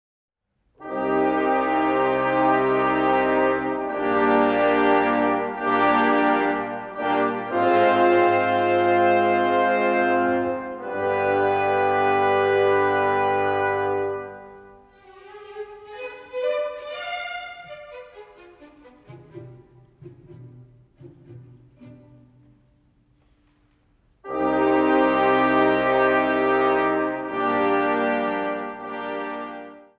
repräsentative Live-Aufnahmen